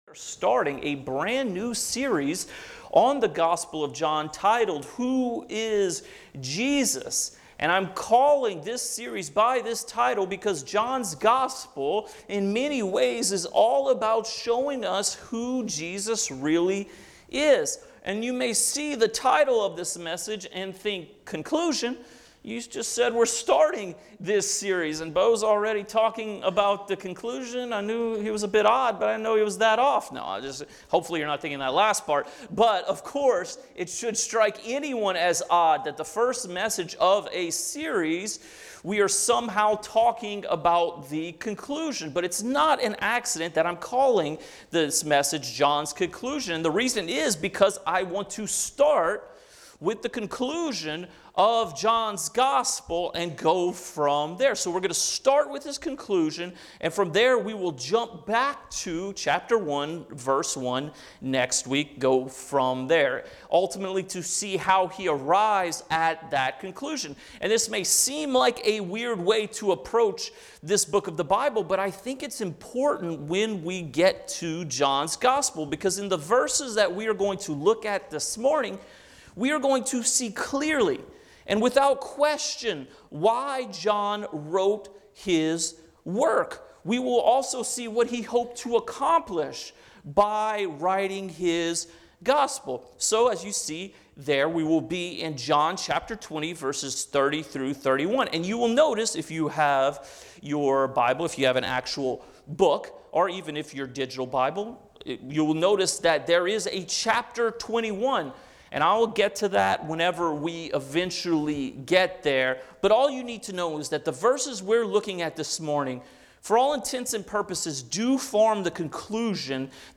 Sermons | Fellowship Baptist Church